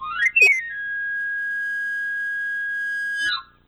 Una flauta callejera del presente resistente y una Cantante Ambulante del pasado perdida en la época de la Lira Italiana se encuentran, coinciden en Mib menor Dórico afinados a 432Hz y generan las 2 melodías temáticas (fragmentos temáticos rudimental)
Flauta-de-afilador-A.wav